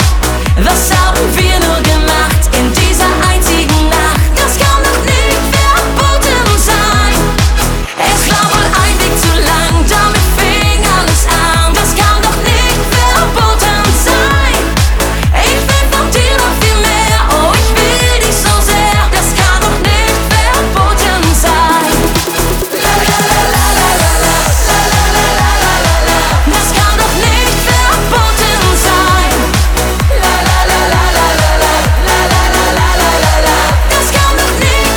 Жанр: Поп музыка
German Pop